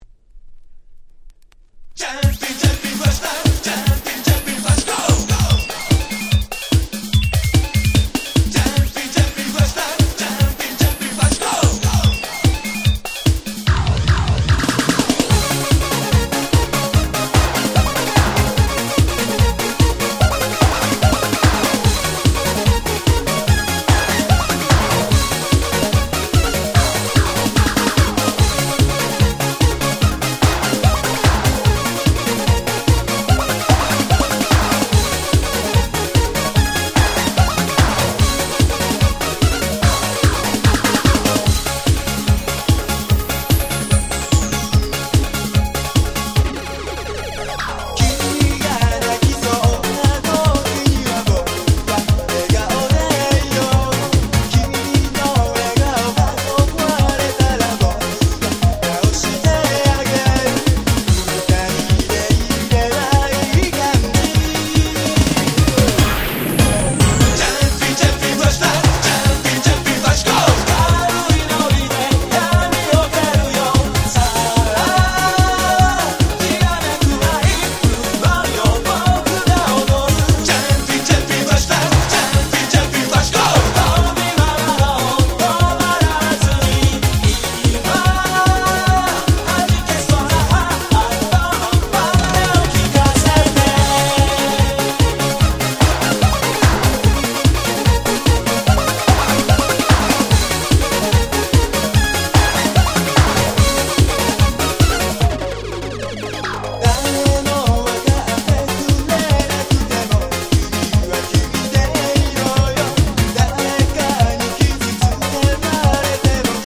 95' Smash Hit J-Pop / Super Euro Beat !!